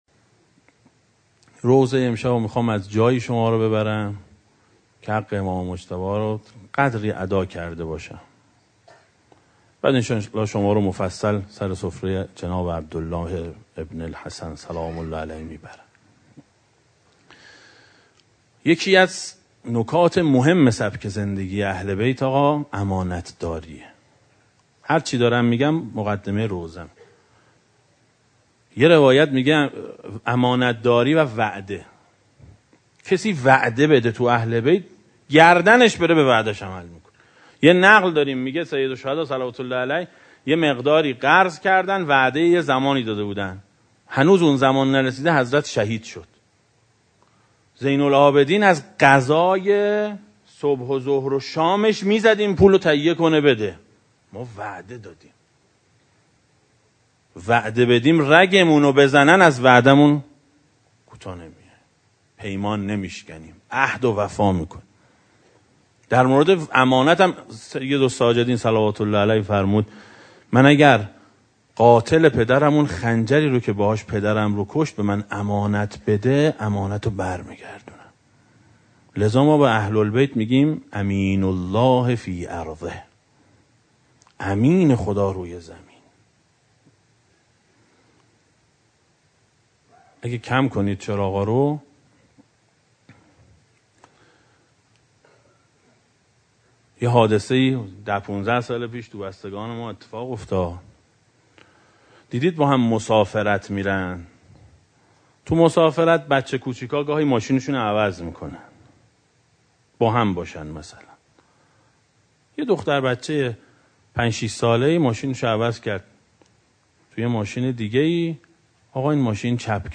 روضه شب پنجم محرم سال 1395 ـ مجلس دوم
دسته: امام حسین علیه السلام, روضه های اهل بیت علیهم السلام, سخنرانی ها